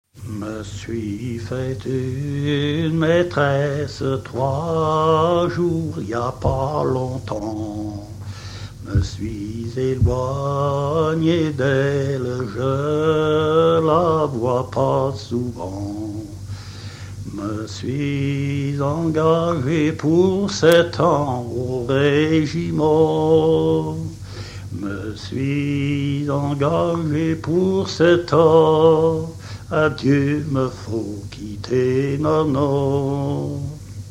Givrand
chansons traditionnelles
Pièce musicale inédite